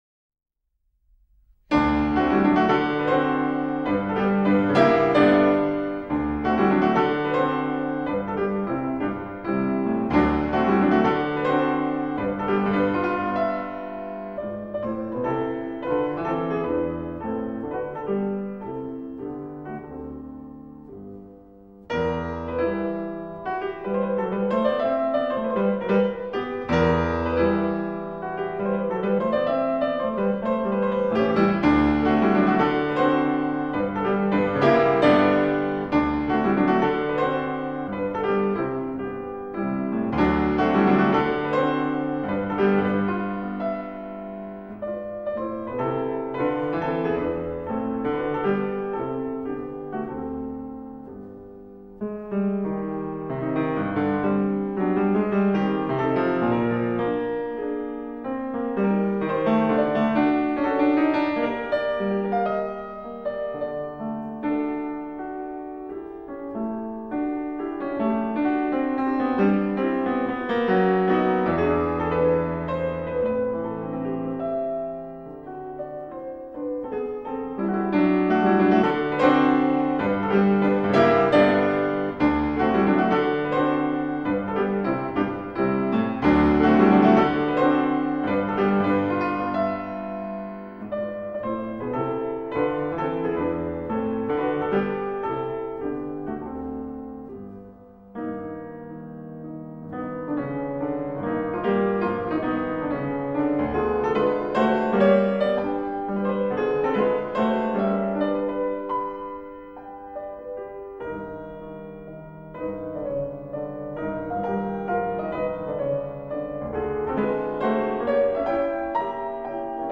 Vol III - Mazurkas
钢琴